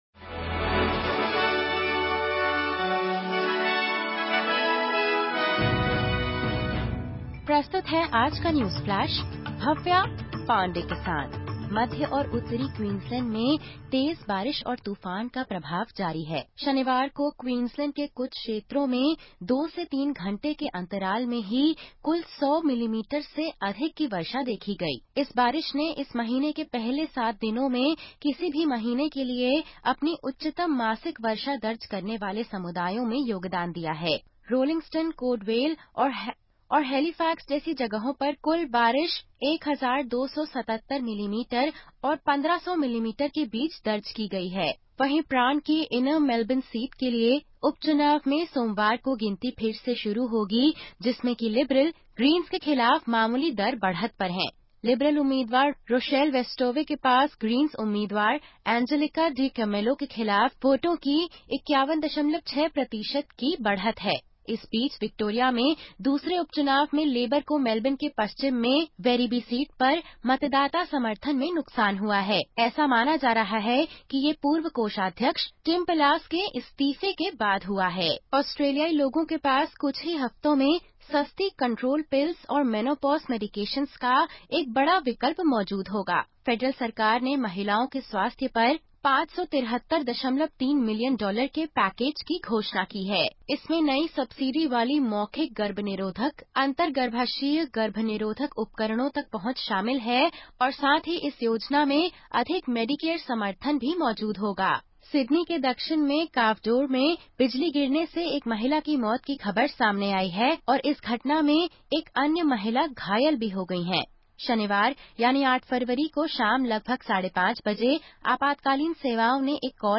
सुनें ऑस्ट्रेलिया और भारत से 09/02/2025 की प्रमुख खबरें।